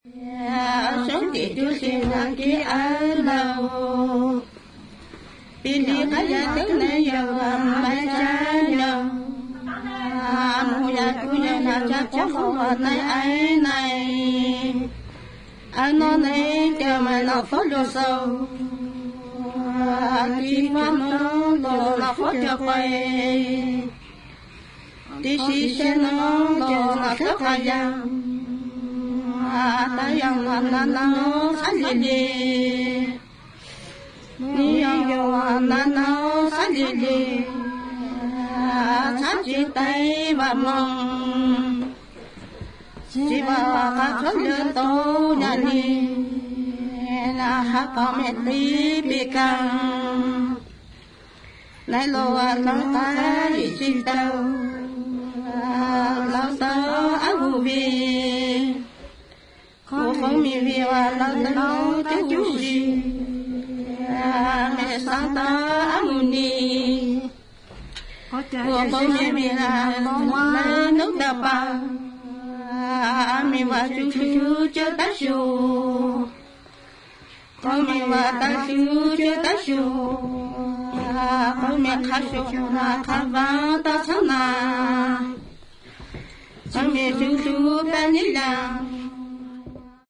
ひょうたん笛、笙、伝統的な弦楽器による音色や人々による唱法が独特の音の調和を生み出している素晴らしいフィールドレコーディング作品。